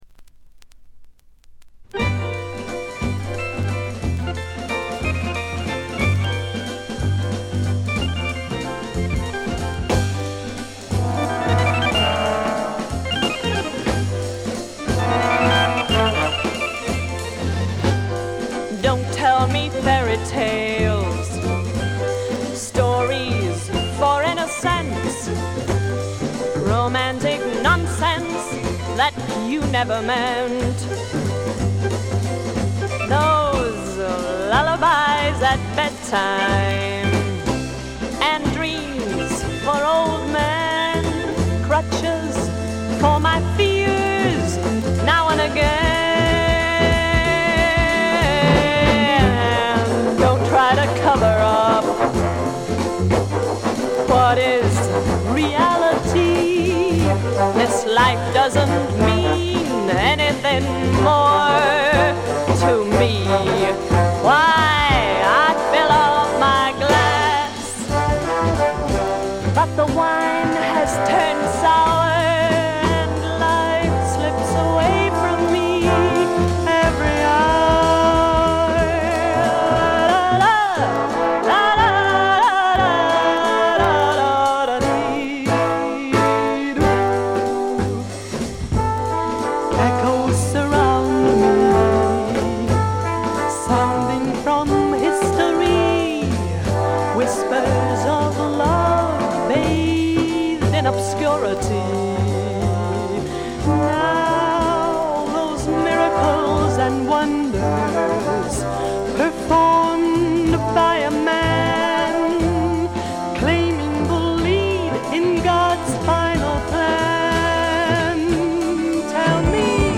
プレスが良くないのか見た目より悪くて、全体にバックグラウンドノイズ、チリプチ多め大きめ、散発的なプツ音少々。
存在感のあるアルトヴォイスがとてもいい味をかもし出して、個人的にも大の愛聴盤であります。
試聴曲は現品からの取り込み音源です。